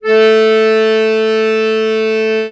ACCORD5 A2-L.wav